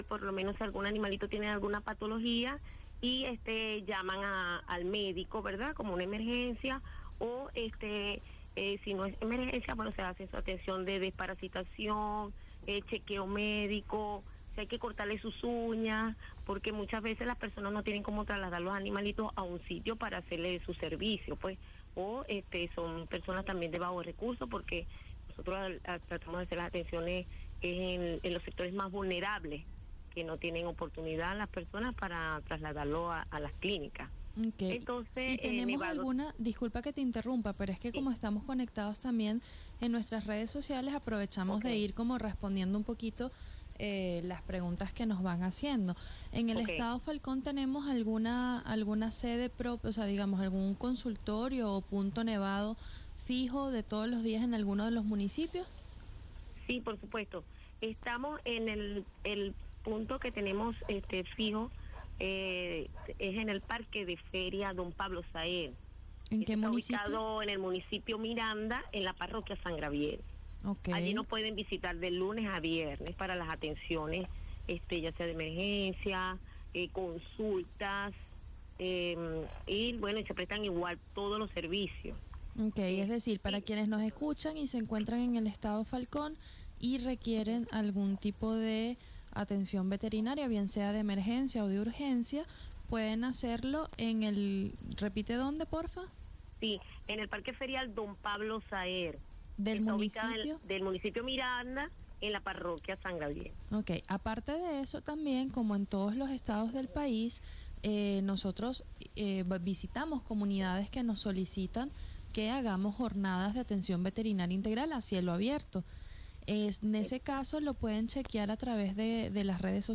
Transmisión simultánea junto a Radio Miraflores.